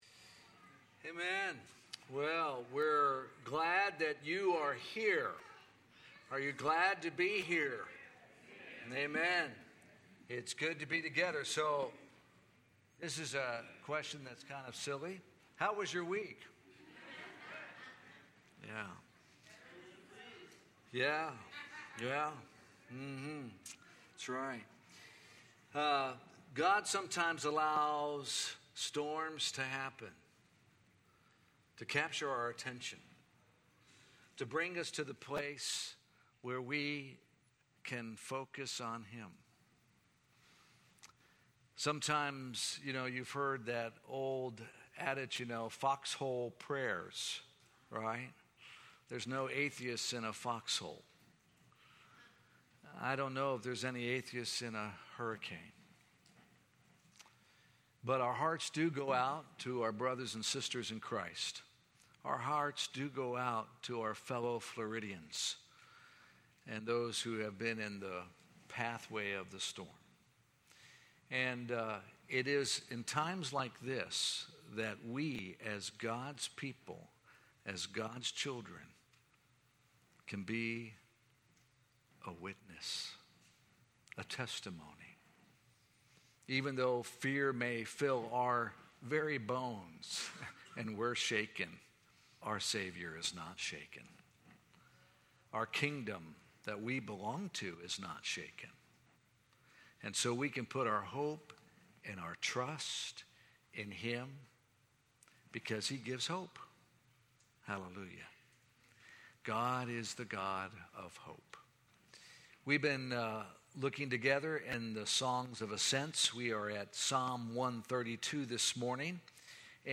Sermons | Alliance church of Zephyrhills